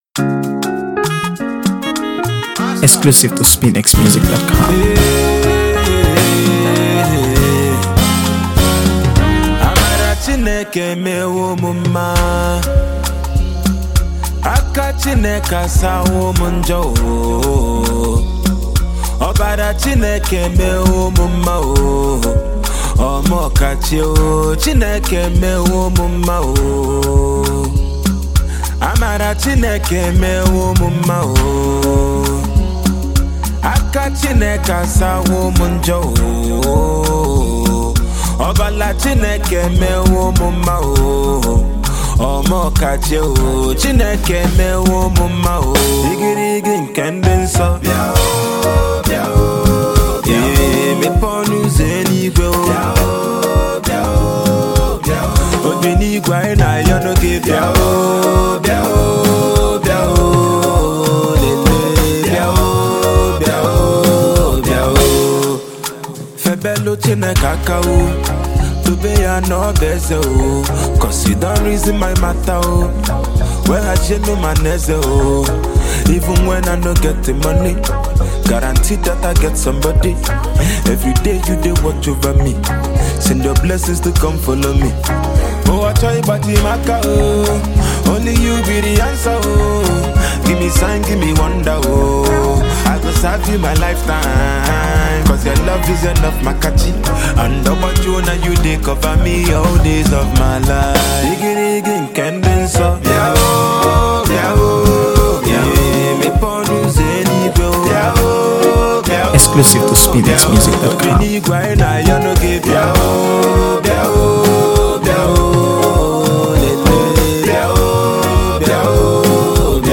Multi-talented Nigerian Indigenous rapper